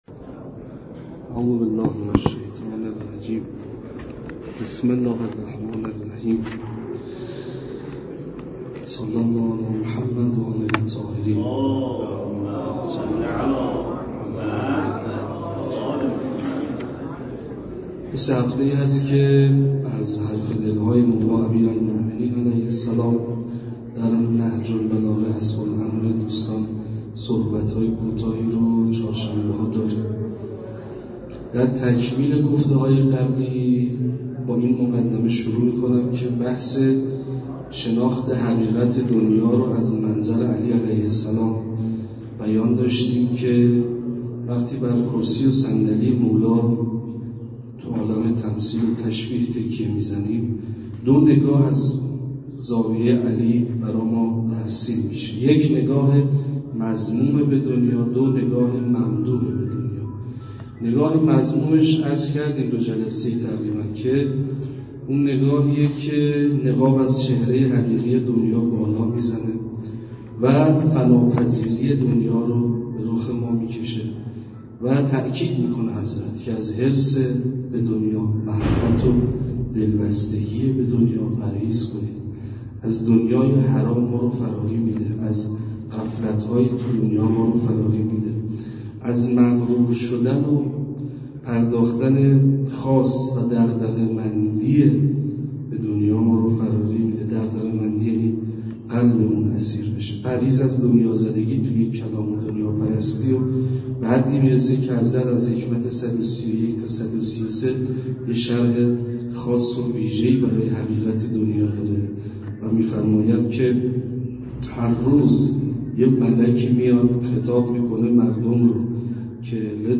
سخنرانی
در مسجد دانشگاه کاشان